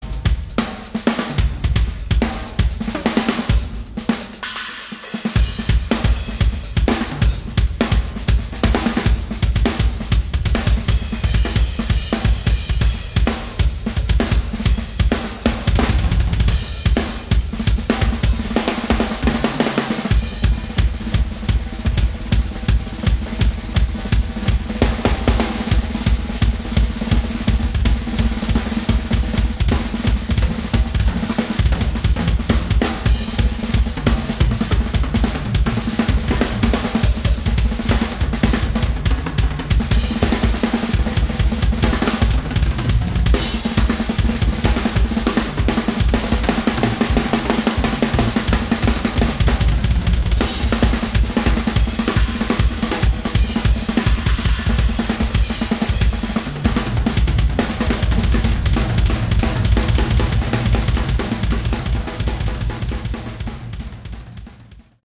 Category: Hard Rock
drums